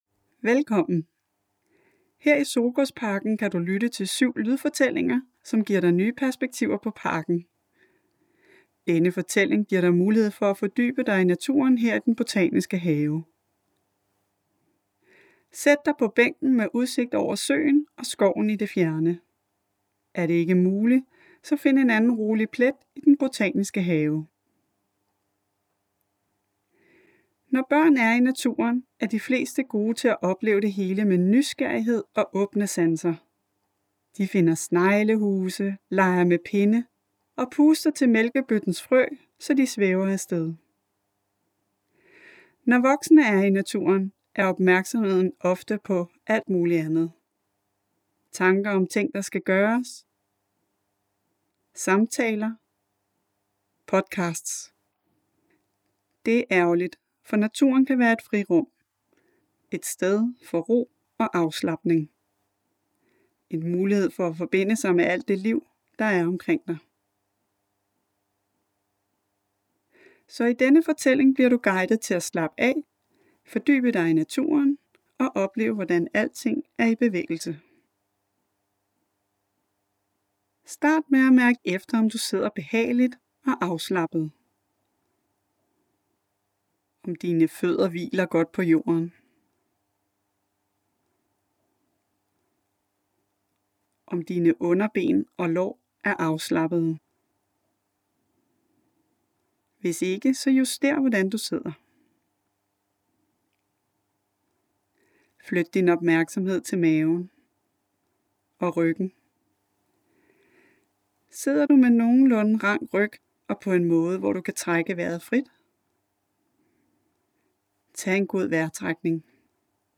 Lydfortællingen Lad os være mennesker! lader dig opleve parken gennem spændende fortællinger, hvor du undervejs inviteres til at gå på opdagelse og reflektere.